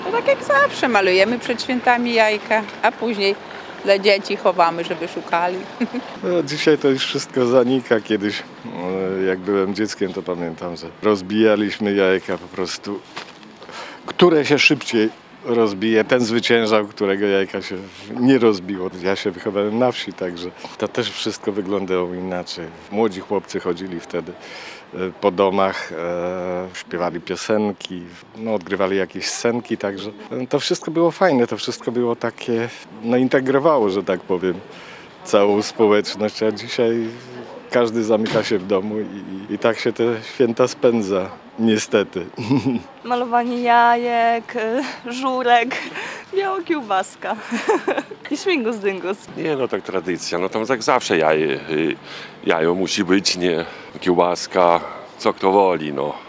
Malowanie jajek, święcenie pokarmów i śmigus dyngus – to najpopularniejsze zwyczaje związane z Wielkanocą. Zapytani przez Radio 5 mieszkańcy Suwałk twierdzą jednak, że tradycje zanikają i kiedyś kultywowano je bardziej.